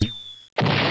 Special_Bomb_New.mp3